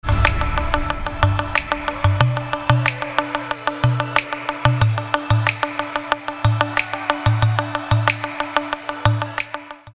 • Качество: 320, Stereo
dance
Electronic
EDM
без слов